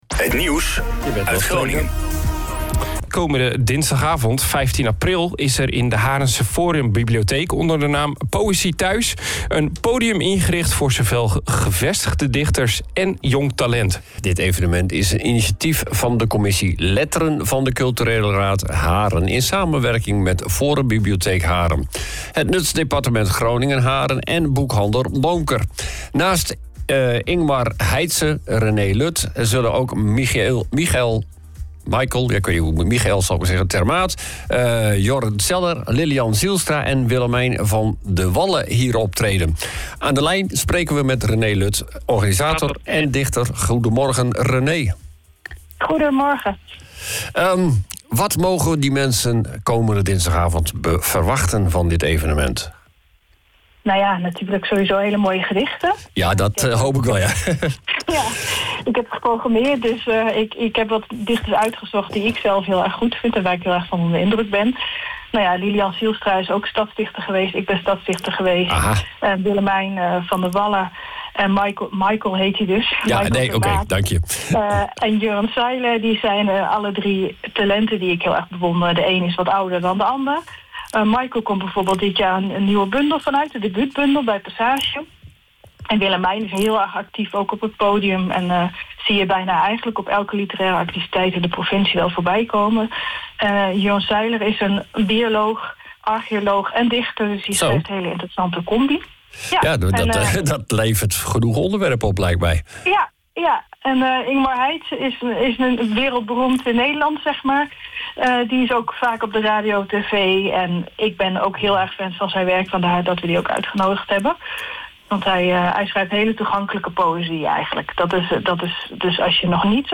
stond de Ochtendshow telefonisch te woord over het evenement en de aanwezige dichters: OOG Ochtendshow